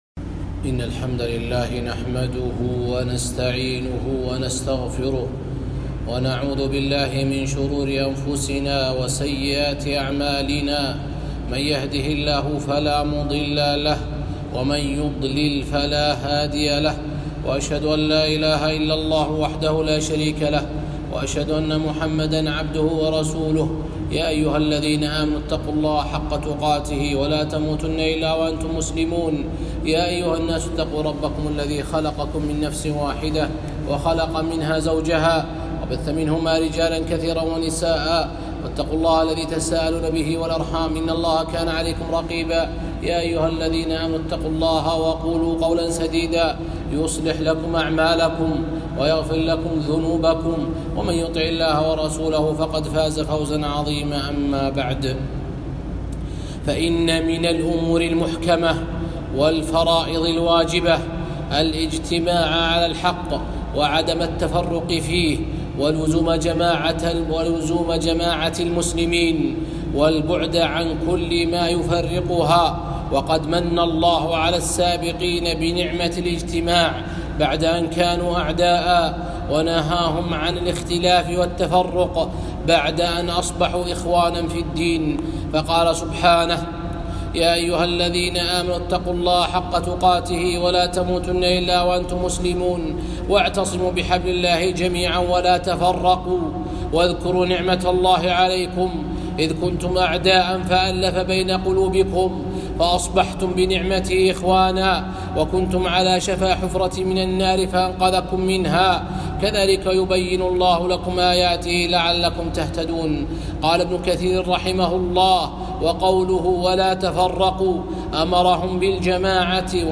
خطبة - نعمة الاجتماع